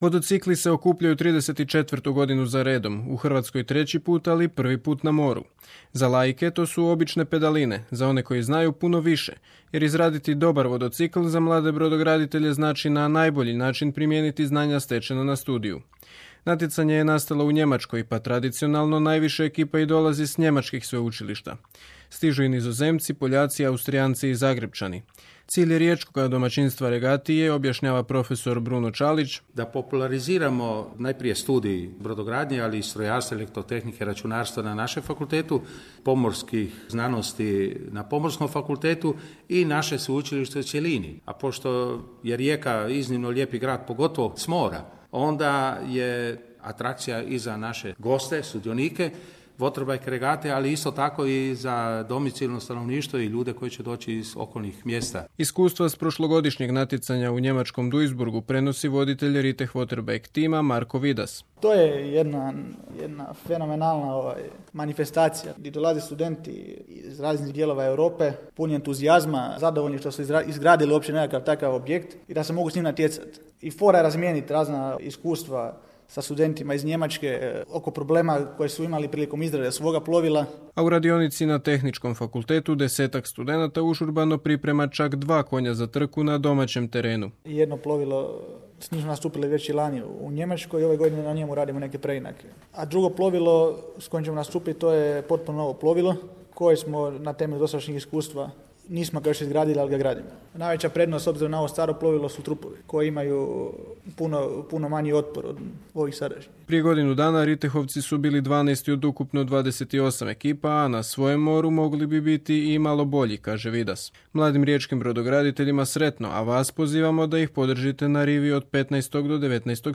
Intervju Riteh waterbike team-a na radio Rijeci, u subotu 4.5. u 15 sati.